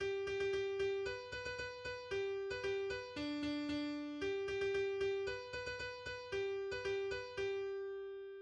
ビューグル
信号ラッパ